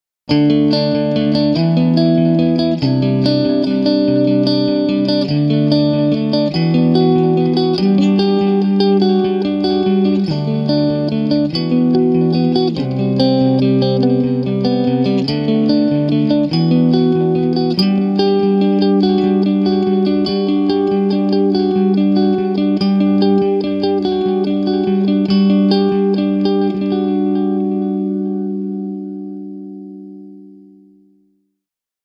The soundclips are organized by amp type and feature a wide variety of tonal possibilities that are possible with our amps.
Clean Ballad Fender Strat Eric Clapton Clean 970Kb
Recording setup: Marshall 1960 4x12 cabinet with Shure SM57, AKG 414, Sennheiser md 421 and Audio Tecnica 5040 mics
Clean.mp3